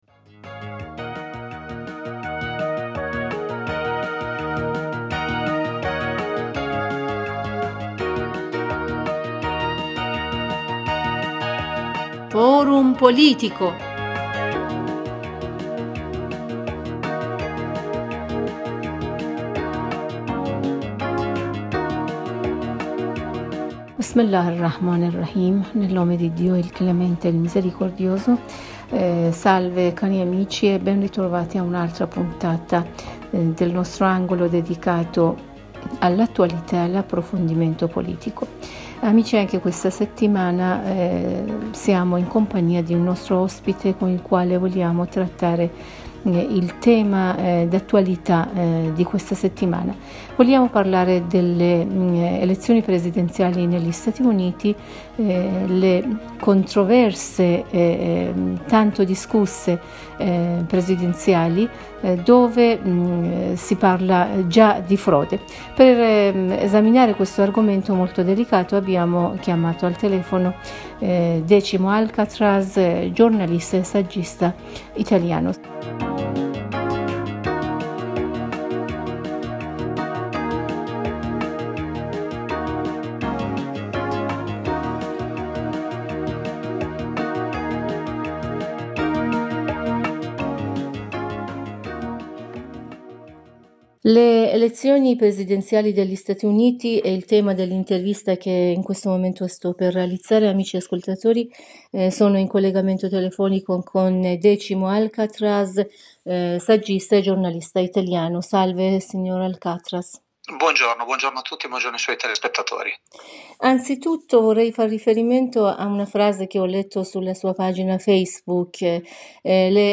in un collegamento telefonico